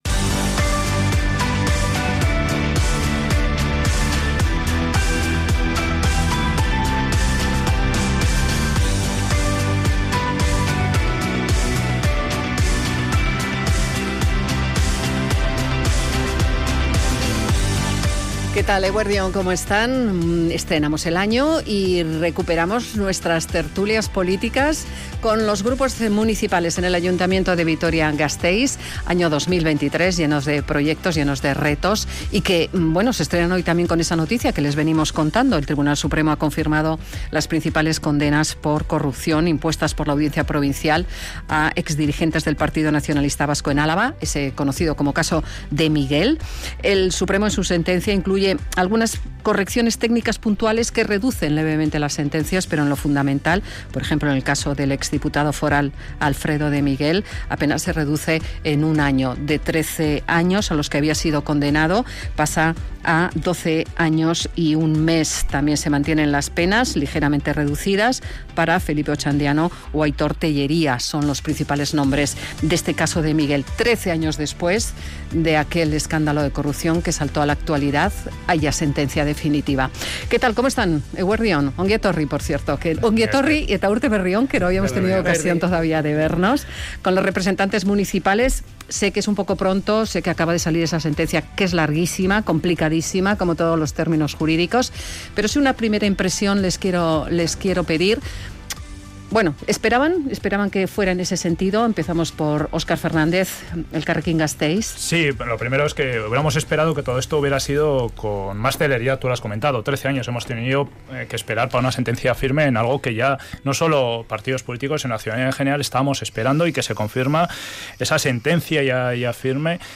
Es una de las cuestiones sobre las que han debatido las y los representantes de los grupos políticos del Ayuntamiento de Vitoria.